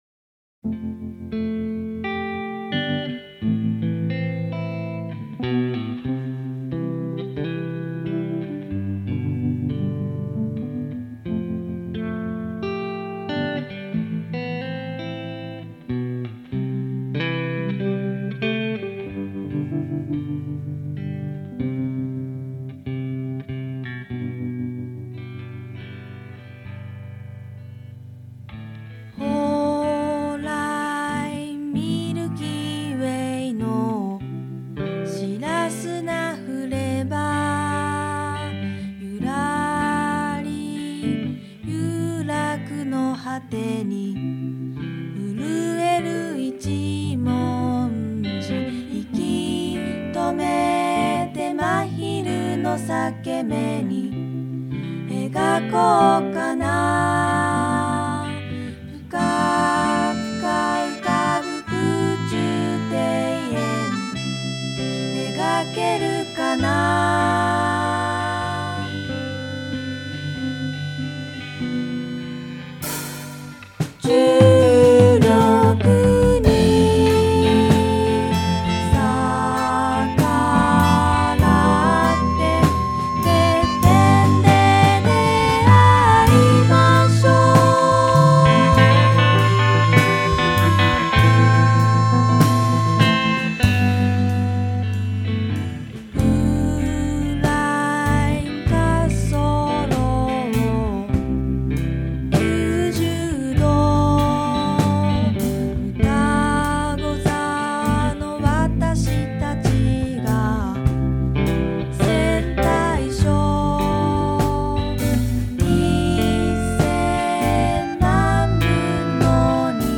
オルタナ感覚を活かしたサイケデリック～フォーク！